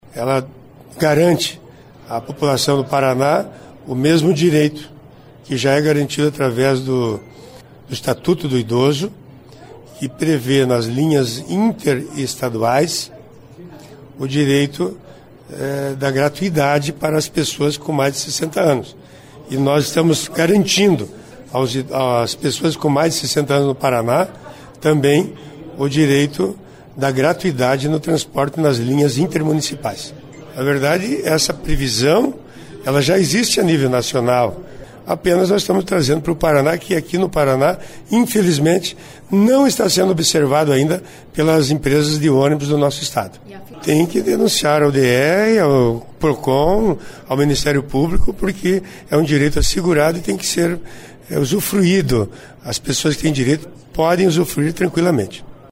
Ouça entrevista com  o autor da lei.